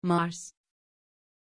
Aussprache von Mars
pronunciation-mars-tr.mp3